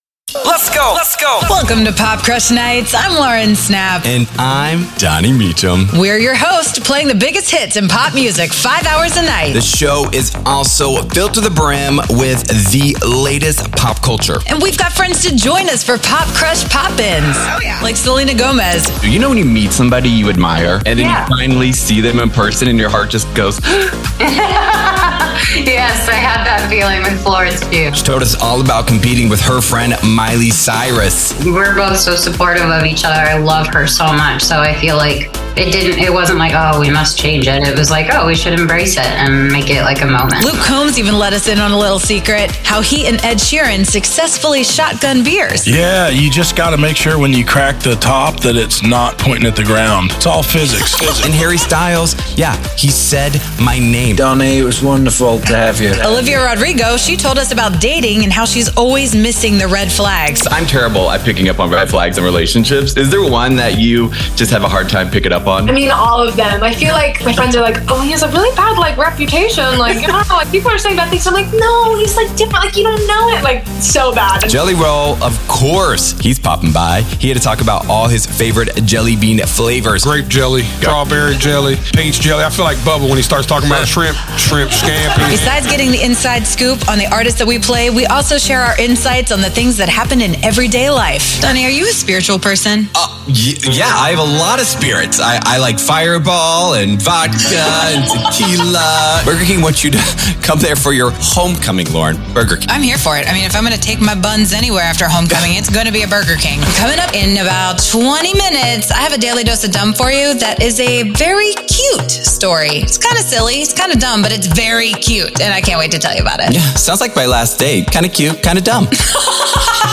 Broadcasting coast to coast, this upbeat and fun 5-hour show features the biggest in pop music, pop culture, and PopCrush PopIns from the most prominent artists on the planet.